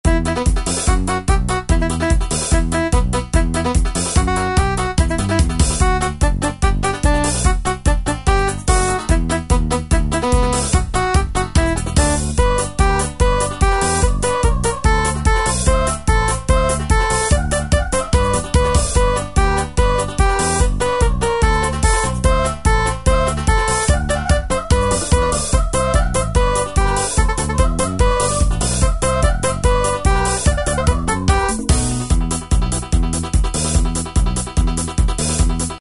Tempo: 146 BPM.
MP3 with melody sample 30s (0.5 MB)free
MP3 with lead melody play melody usually by flute.